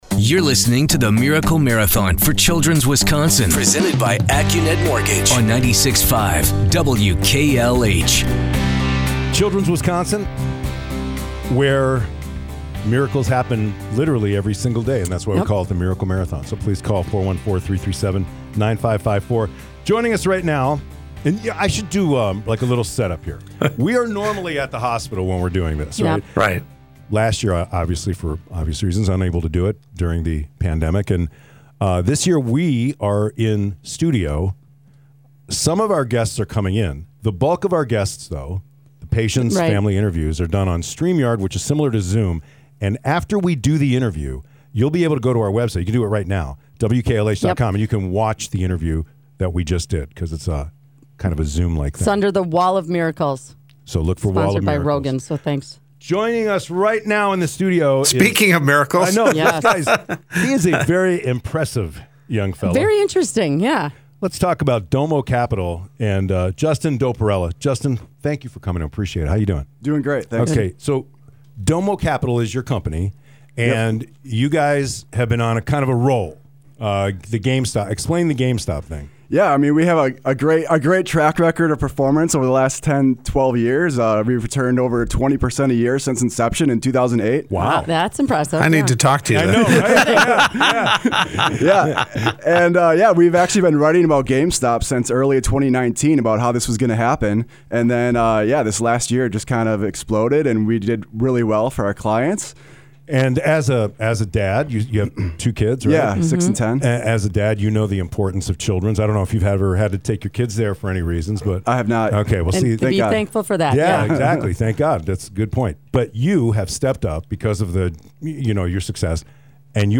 May 20, 2021 - DOMO Capital Interview with WKLH in Support of Children's Wisconsin